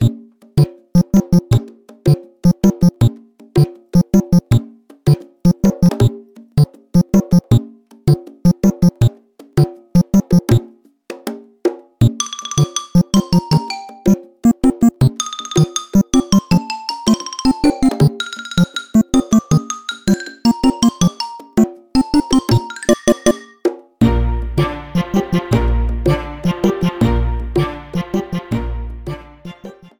The medley